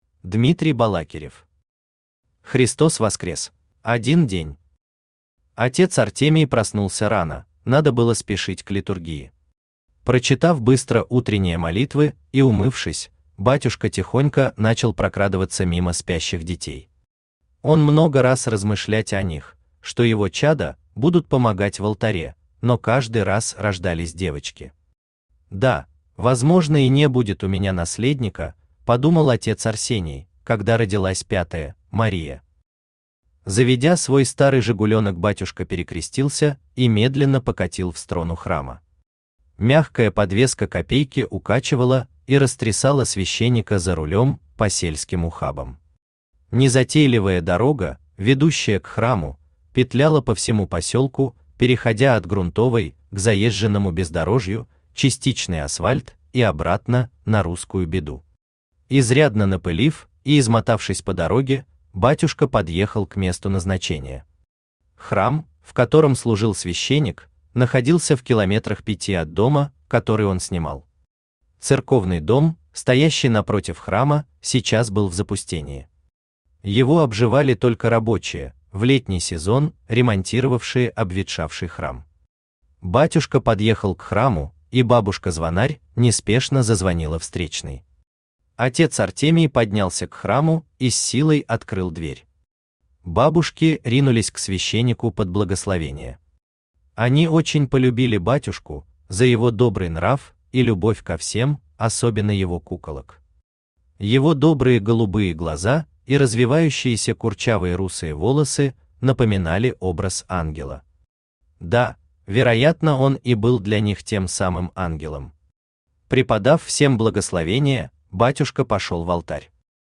Аудиокнига Христос Воскрес | Библиотека аудиокниг
Aудиокнига Христос Воскрес Автор Дмитрий Балакирев Читает аудиокнигу Авточтец ЛитРес.